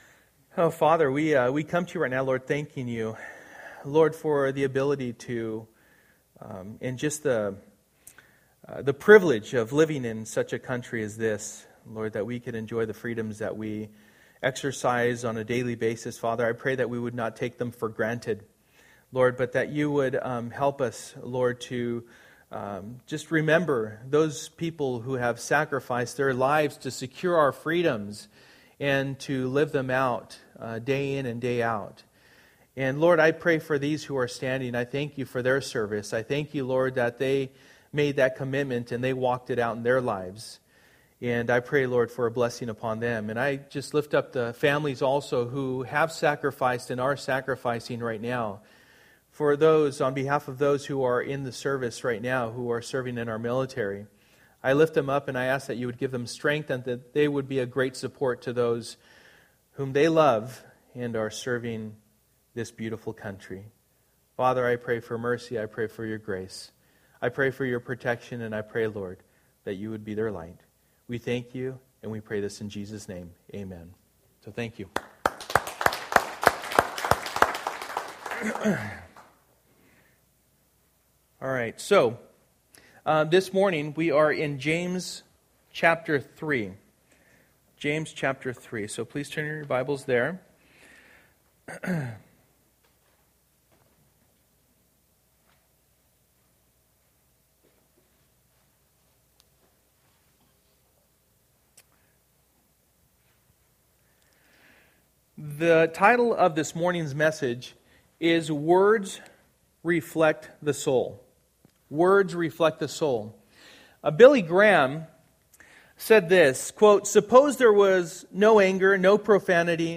James 3:1-18 Service: Sunday Morning %todo_render% « God